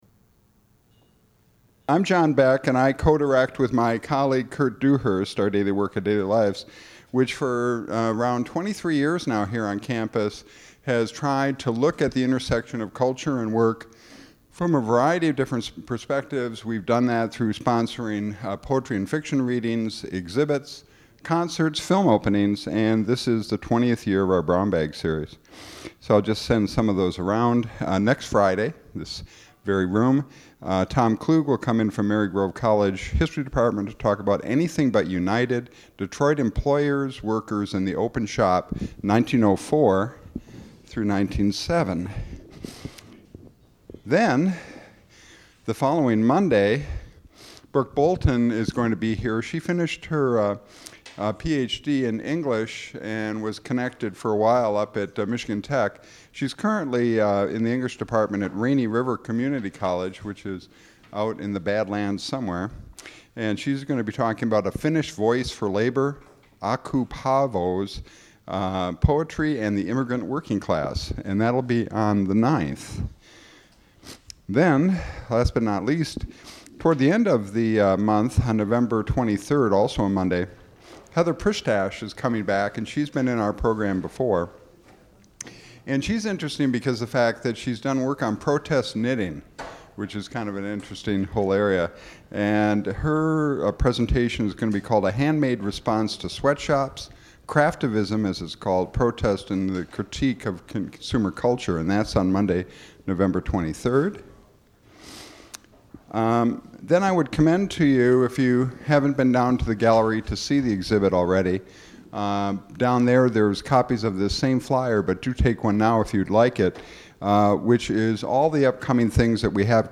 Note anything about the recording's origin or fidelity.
Held at the MSU Museum Auditorium.